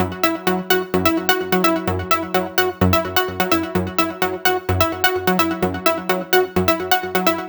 VEE Melody Kits 37 128 BPM Root F#.wav